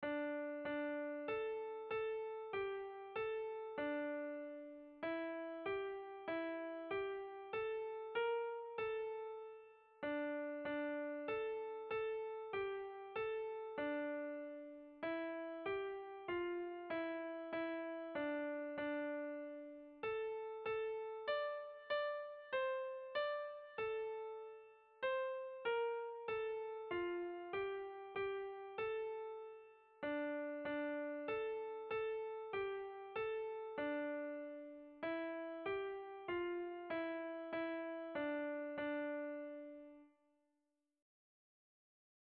Erlijiozkoa
Zortziko txikia (hg) / Lau puntuko txikia (ip)
A1A2BA2